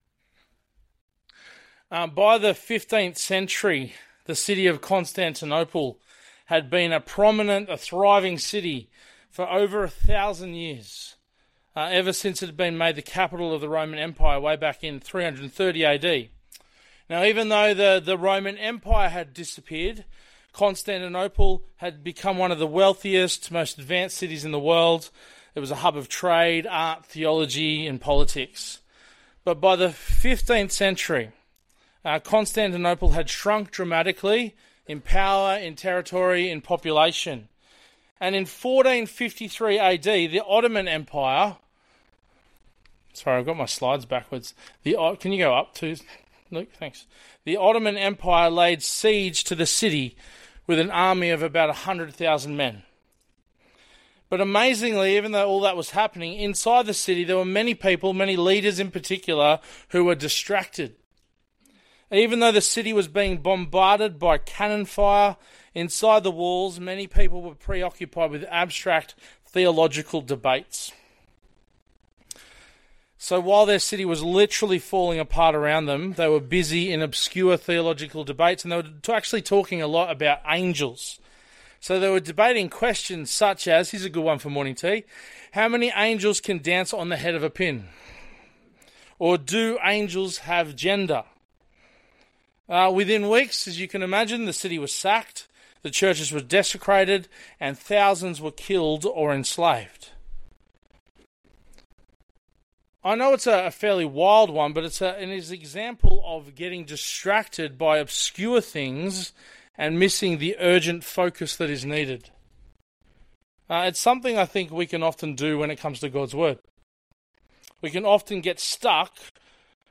Bible Talks | Bairnsdale Baptist Church